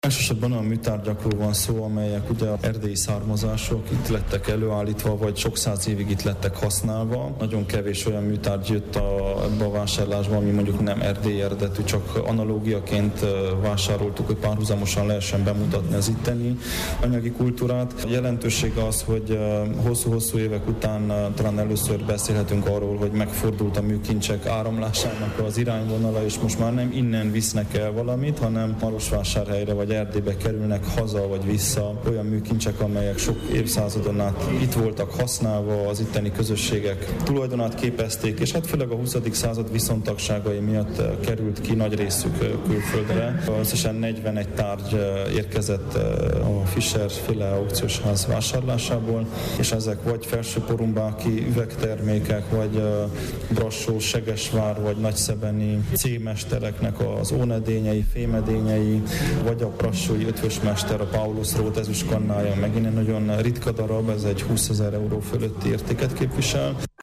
A marosvásárhelyi Történelmi-Régészeti Vármúzeum Konferenciatermében ma az újságíróknak mutatták be az utóbbi három év műtárgy vásárlásait, ezek közt vannak fegyverek, kínai szekrény, ezüstkanna, aranyozott cibórium, szász kerámia, erdélyi ónedények és üvegtárgyak.
Soós Zoltán múzeumigazgatót hallják.